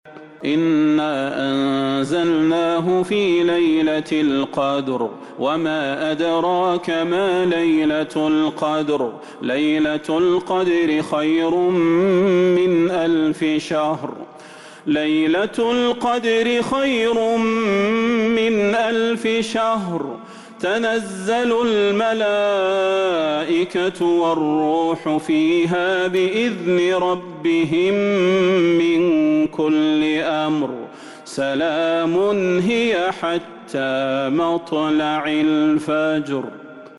سورة القدر Surat Al-Qadr من تراويح المسجد النبوي 1442هـ > مصحف تراويح الحرم النبوي عام 1442هـ > المصحف - تلاوات الحرمين